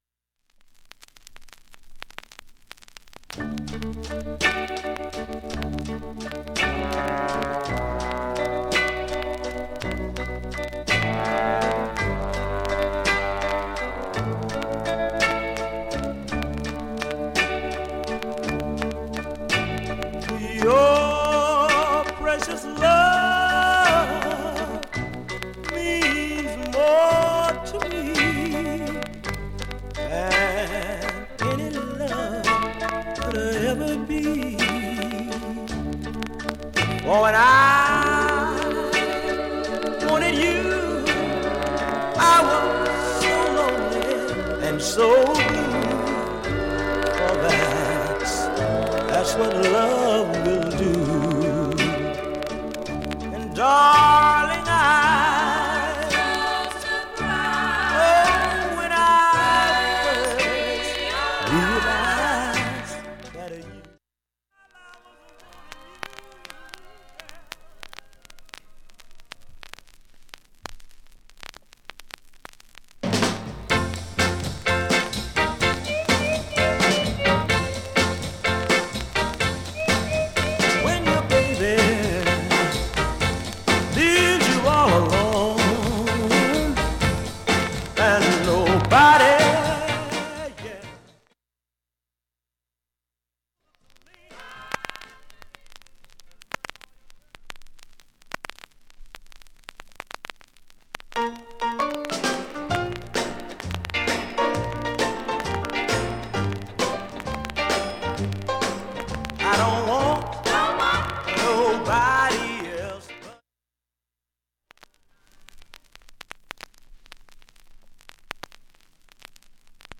ずっとバックチリプツが出ています、
プツ自体はストレスはありません。
SHURE M 44G 針圧３グラムで
1,A-1序盤チリプツ多め中盤以降良好
◆ＵＳＡ盤オリジナル Mono